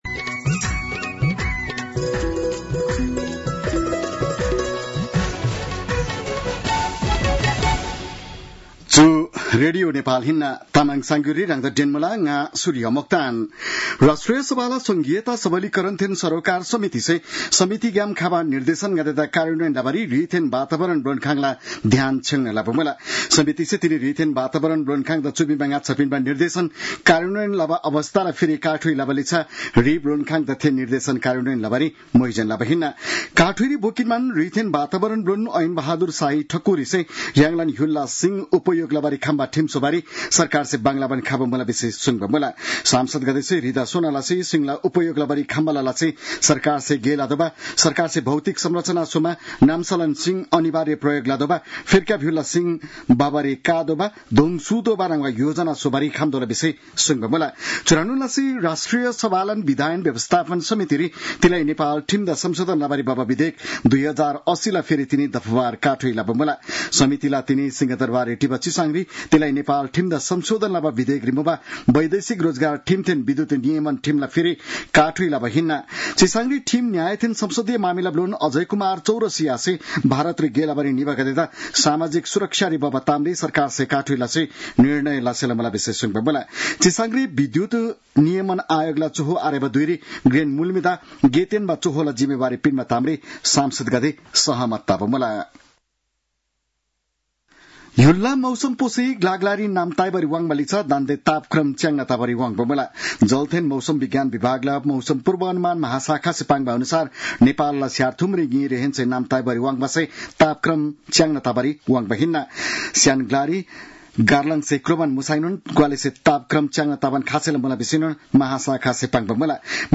An online outlet of Nepal's national radio broadcaster
तामाङ भाषाको समाचार : २९ जेठ , २०८२